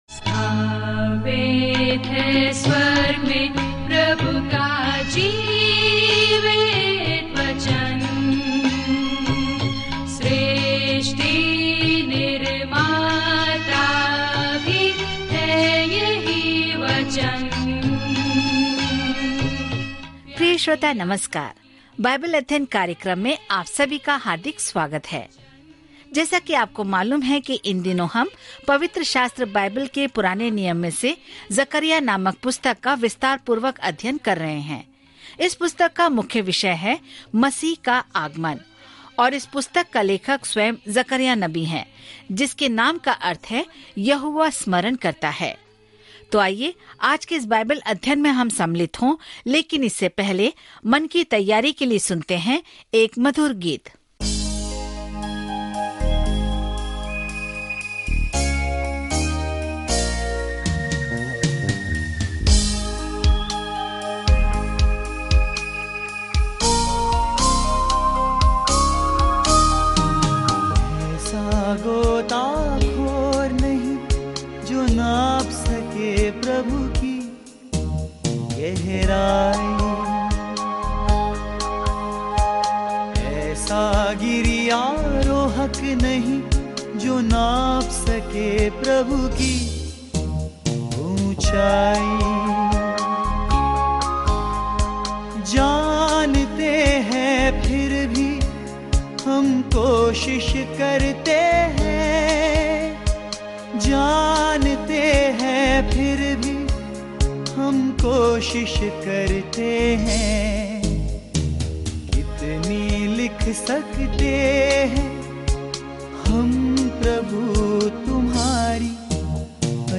पवित्र शास्त्र जकर्याह 4:4-14 दिन 8 यह योजना प्रारंभ कीजिए दिन 10 इस योजना के बारें में भविष्यवक्ता जकर्याह लोगों को भविष्य की आशा देने के लिए ईश्वर के वादों के दर्शन साझा करते हैं और उनसे ईश्वर के पास लौटने का आग्रह करते हैं। जकर्याह के माध्यम से दैनिक यात्रा करें क्योंकि आप ऑडियो अध्ययन सुनते हैं और भगवान के वचन से चुनिंदा छंद पढ़ते हैं।